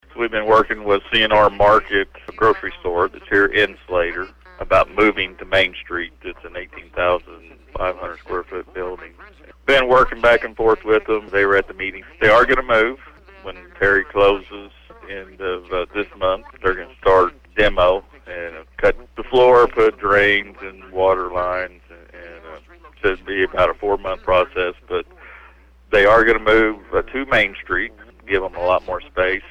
Slater City Administrator Gene Griffith explains.